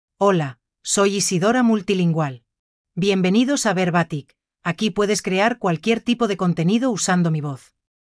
FemaleSpanish (Spain)
Isidora MultilingualFemale Spanish AI voice
Isidora Multilingual is a female AI voice for Spanish (Spain).
Voice sample
Listen to Isidora Multilingual's female Spanish voice.